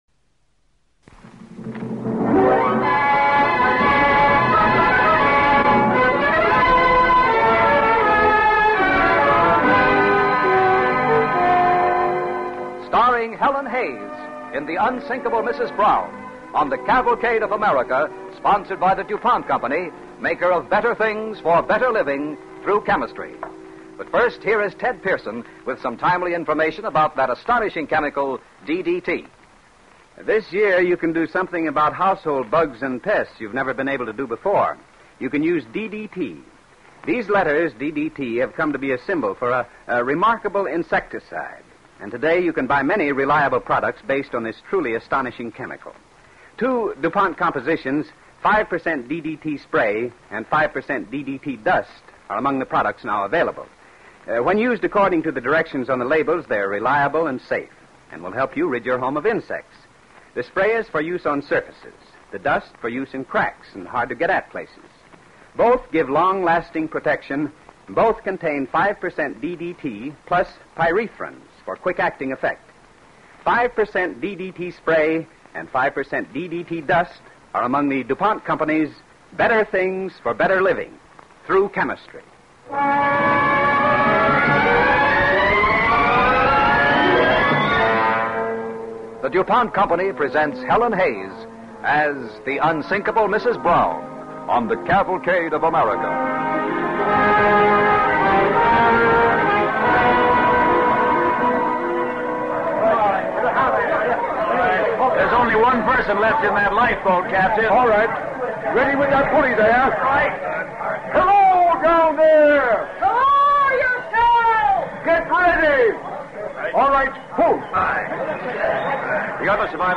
The Unsinkable Mrs. Brown, starring Helen Hayes
Cavalcade of America Radio Program